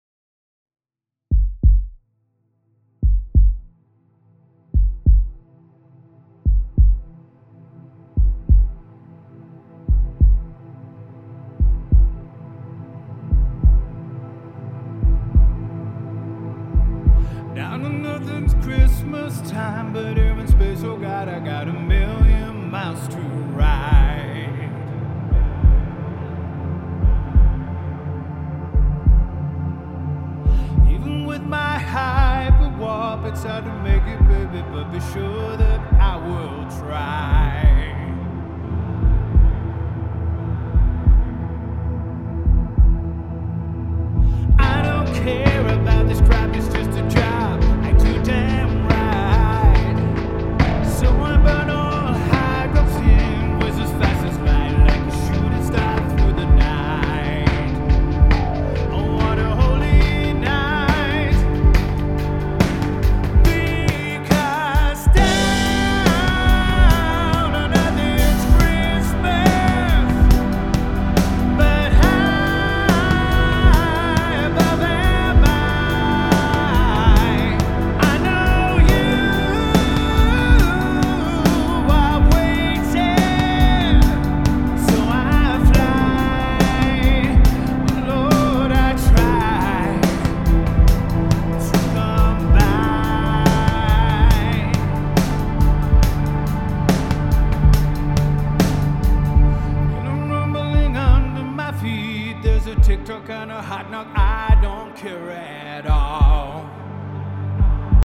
Gitarren totale Schnellschüsse, vergebt.